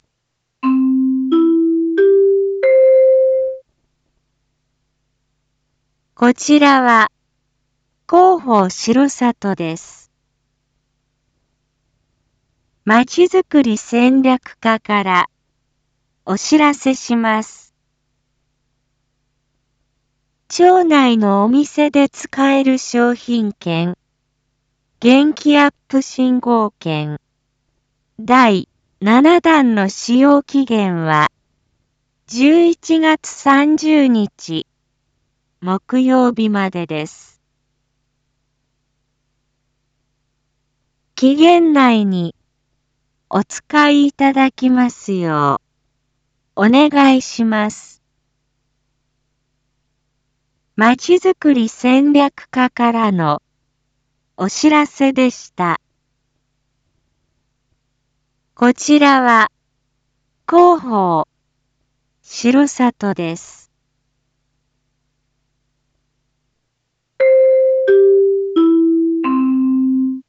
Back Home 一般放送情報 音声放送 再生 一般放送情報 登録日時：2023-11-29 19:01:12 タイトル：元気アップ振興券第７弾の使用期限について インフォメーション：こちらは、広報しろさとです。